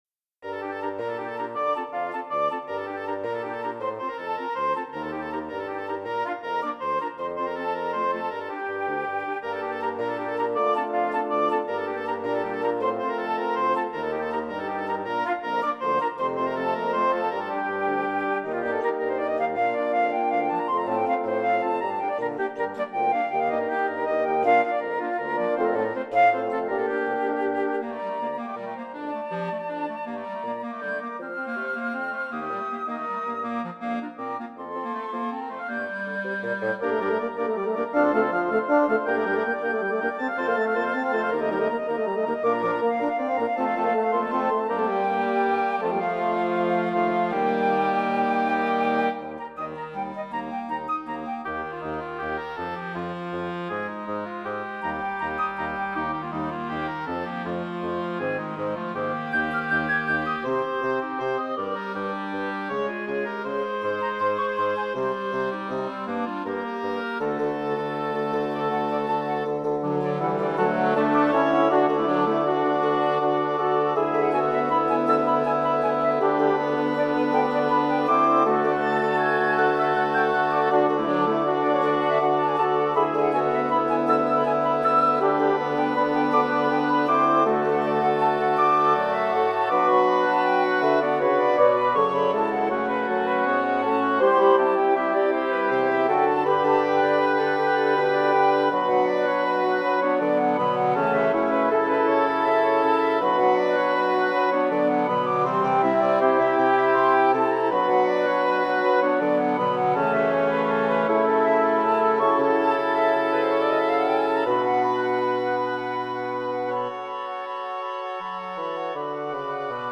Voicing: 10 Winds